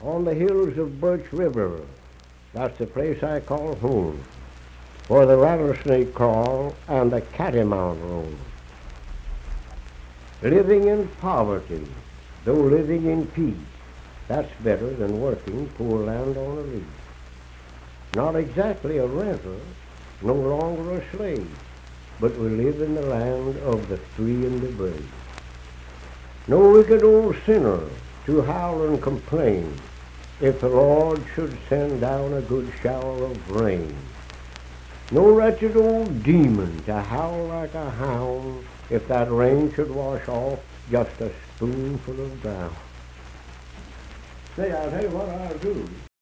Unaccompanied vocal music performance
Voice (sung)
Birch River (W. Va.), Nicholas County (W. Va.)